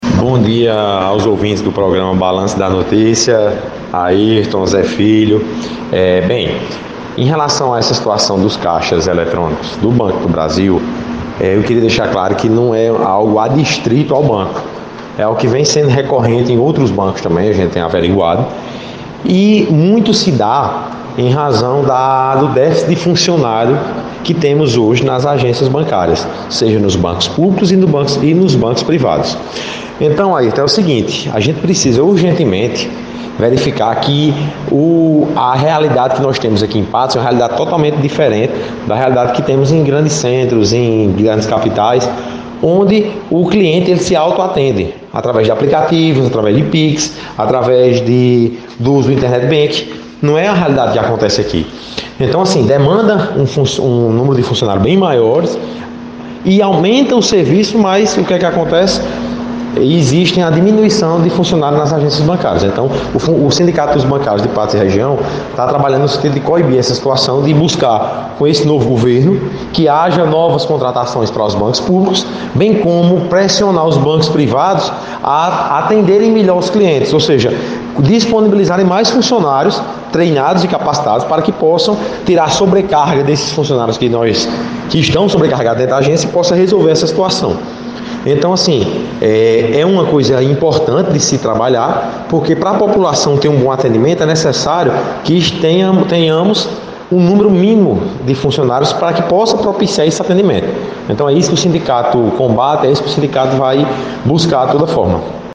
(Áudio: Rádio Itatiunga FM)